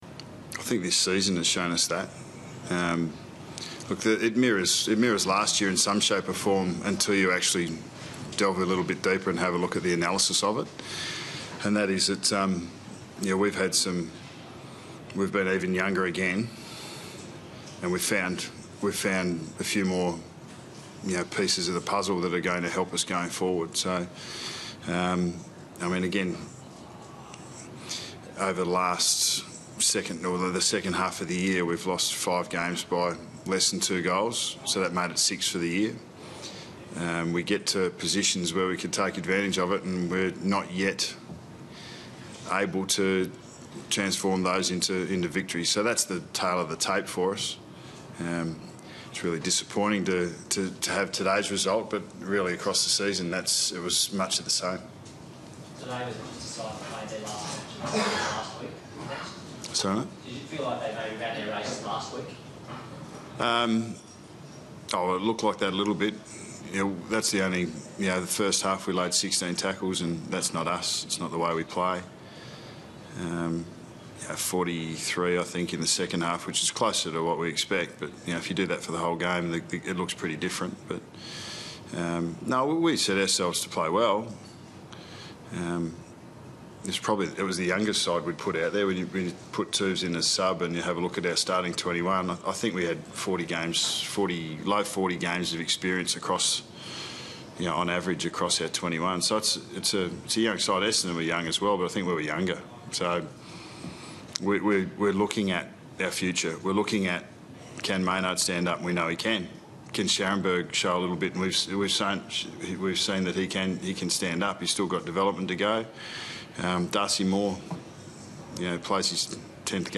Listen to coach Nathan Buckley speak with the media following his side's loss to Essendon in round 23 on Sunday 6 September 2015.